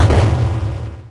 Techmino/media/effect/chiptune/clear_4.ogg at beff0c9d991e89c7ce3d02b5f99a879a052d4d3e
clear_4.ogg